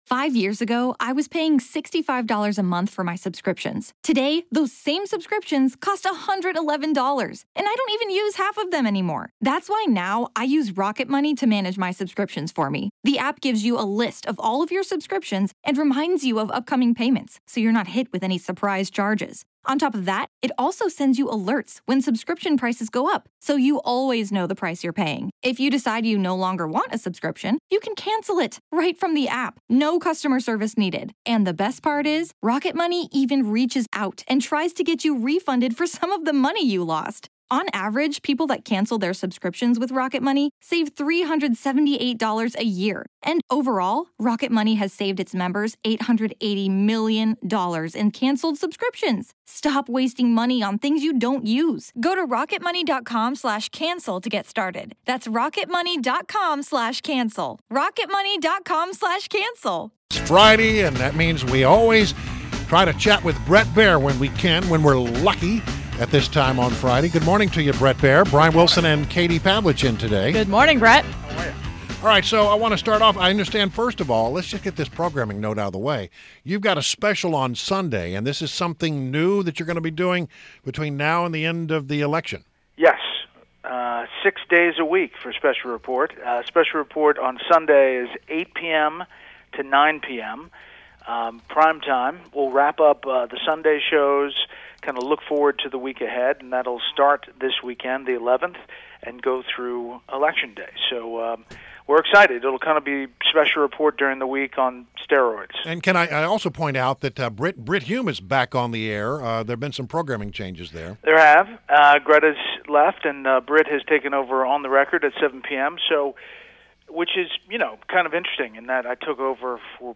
WMAL Interview - BRET BAIER - 09.09.16